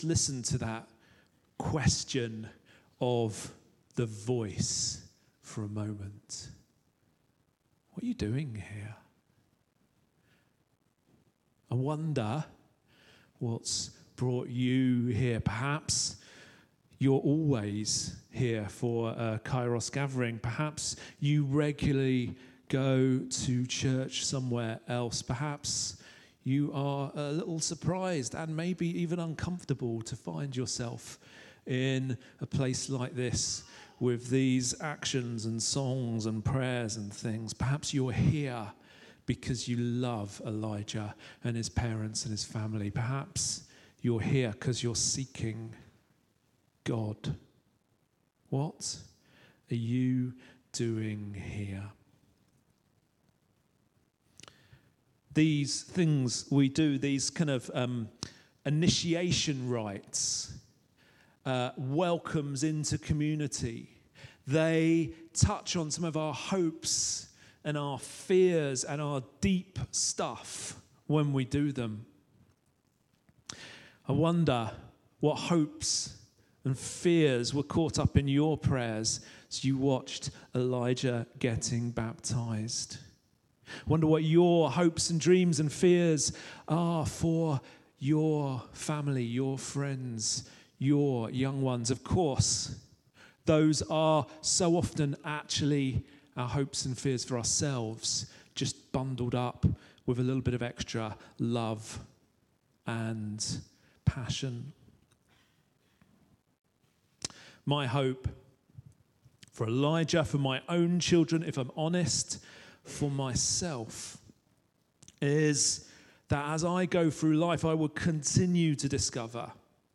sermon 0 Comments